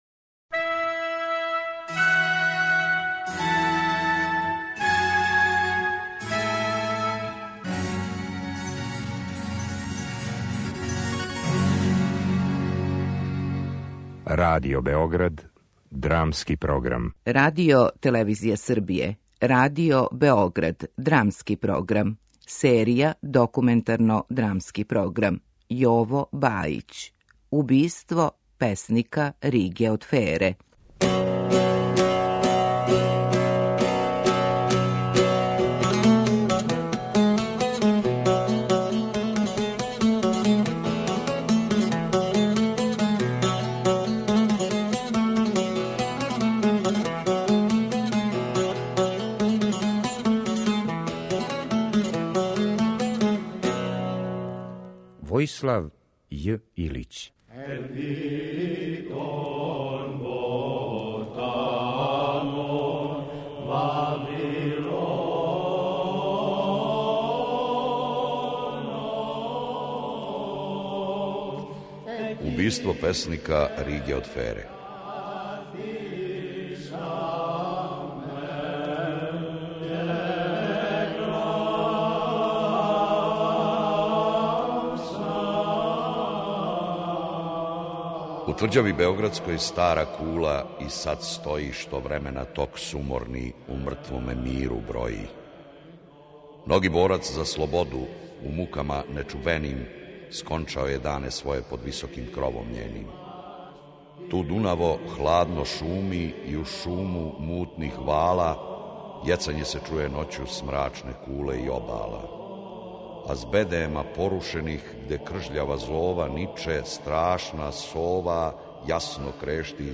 Радио-драма заснована је на документарним белешкама о страдању чувеног грчког песника и револуционара Риге од Фере, погубљеног у Кули Небојши, а присутни су и ликови књижевника Војислава Илића, Иве Андрића и Светлане Велмар Јанковић.